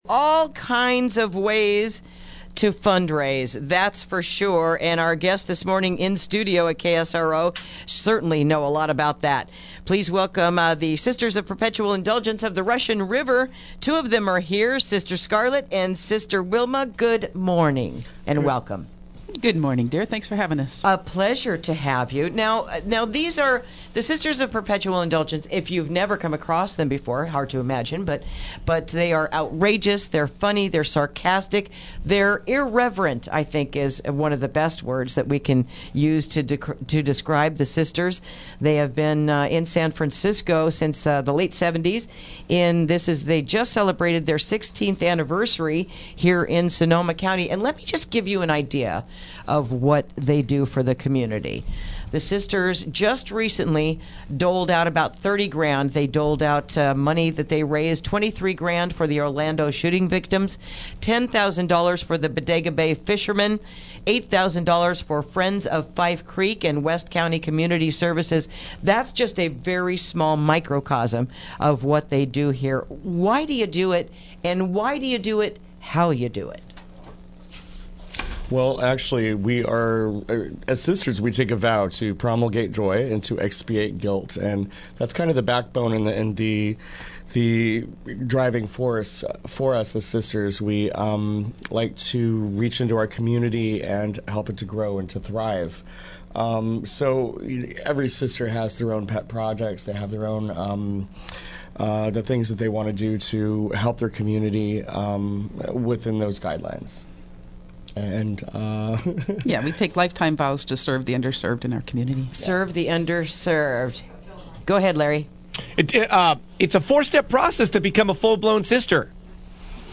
Interview: Sisters of Perpetual Indulgence of the Russian River